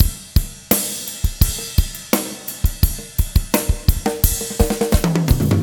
18 rhdrm85trip.wav